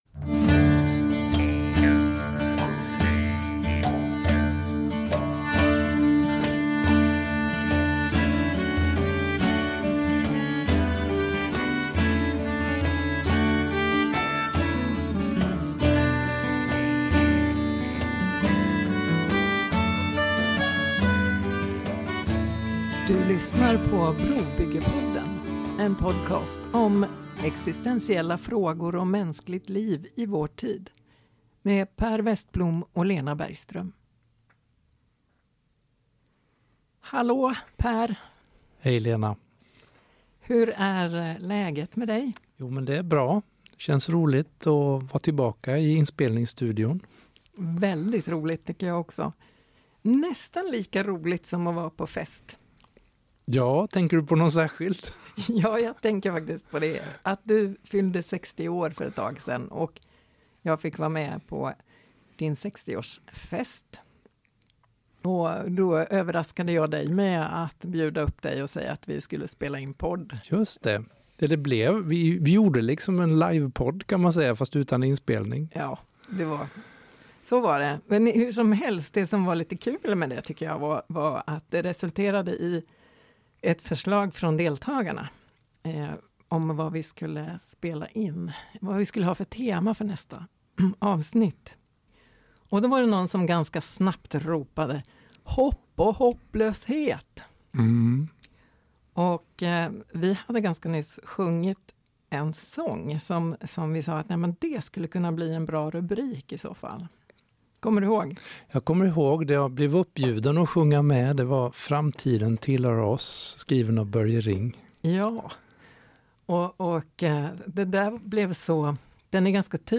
Hur känns det att sjunga sångerna idag? Vilka bilder av Gud, människan och samhället bär vi med oss? Detta är det första avsnittet i en serie samtal under rubriken ”Steg in i framtiden”.